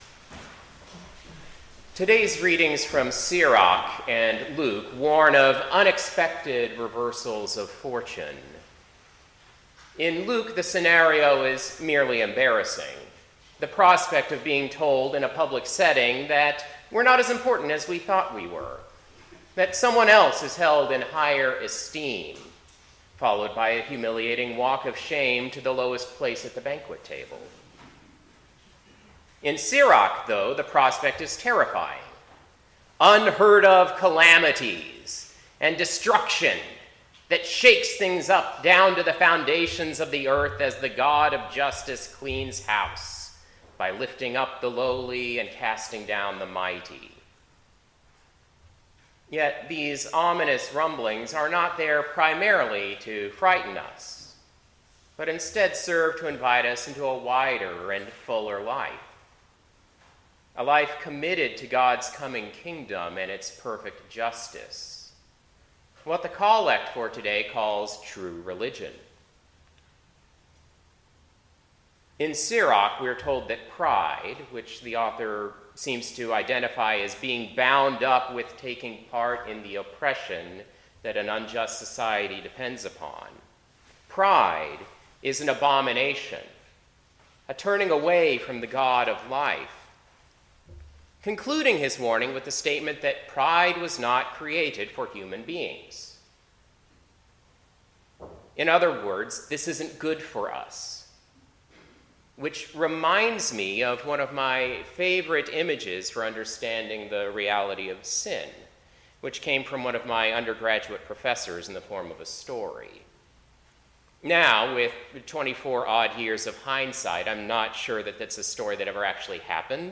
Preacher
10:00 am Service